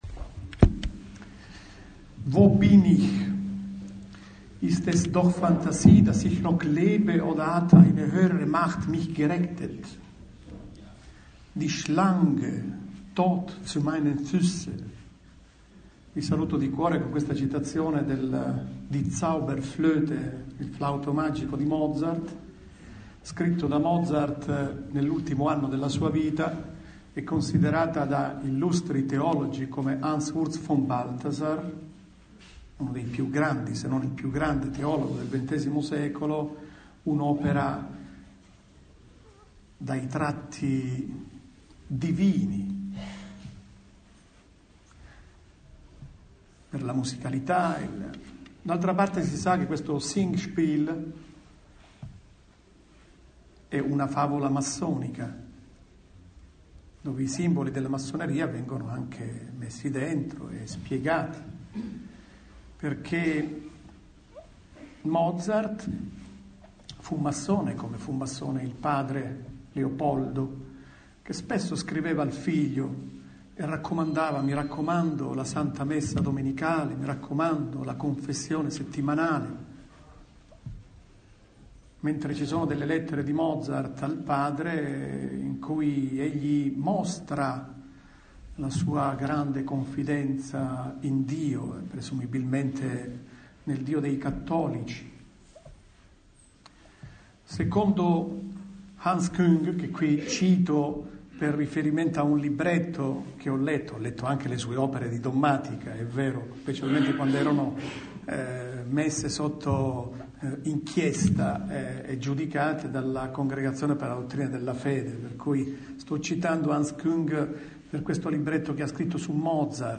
Mons. Staglianò al convegno di Siracusa sulla massoneria: quale vicinanza nell’abissale distanza